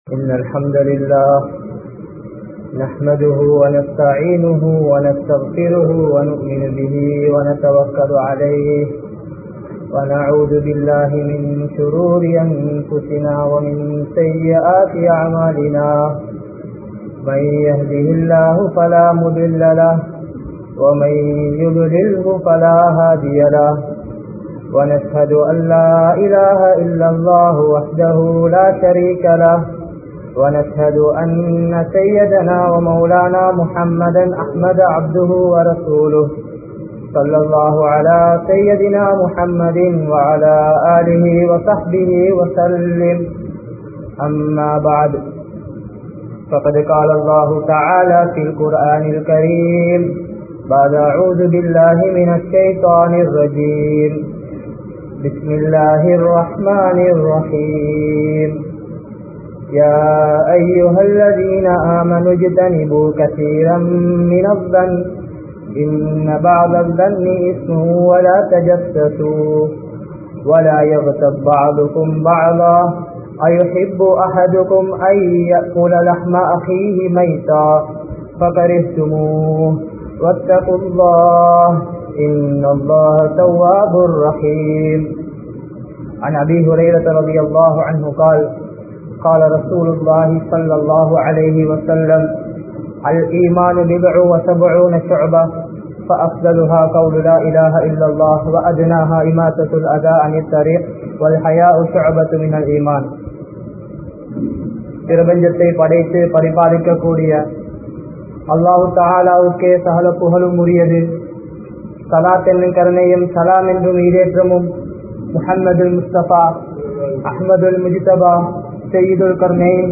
Naavin Vilaivuhal (நாவின் விளைவுகள்) | Audio Bayans | All Ceylon Muslim Youth Community | Addalaichenai